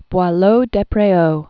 (bwä-lōdĕ-prā-ō), Nicolas 1636-1711.